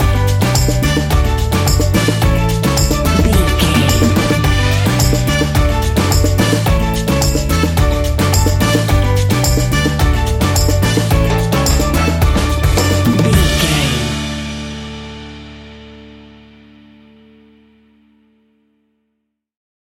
An upbeat and uptempo piece of calypso summer music.
That perfect carribean calypso sound!
Ionian/Major
steelpan
drums
bass
brass
guitar